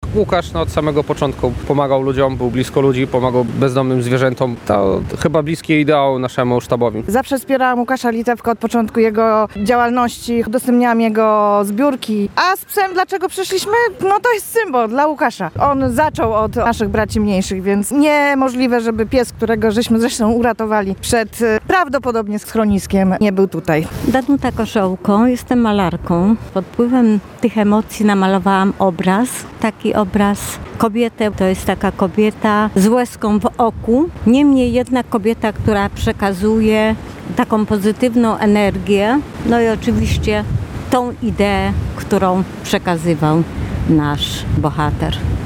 Minutą ciszy bialczanie uczcili pamięć tragicznie zmarłego posła Łukasza Litewki. Zgromadzili się w parku Małpi Gaj, przy drzewie Riada Haidara, aby oddać hołd posłowi, który pomagał najbardziej potrzebującym i bezdomnym zwierzętom. Akcję zorganizował bialski sztab Wielkiej Orkiestry Świątecznej Pomocy.